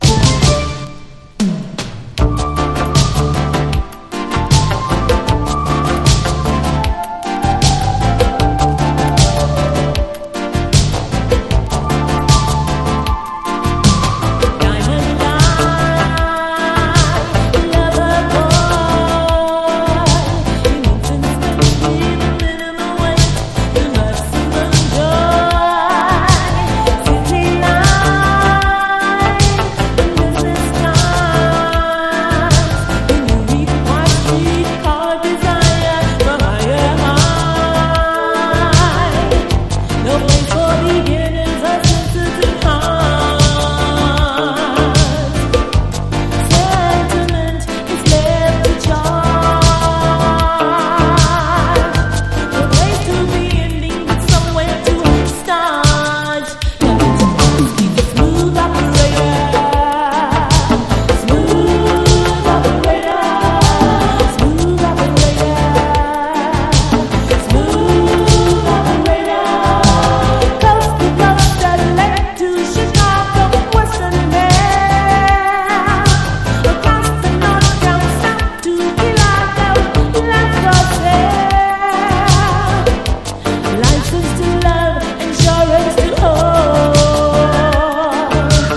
RE-EDIT# LOVERS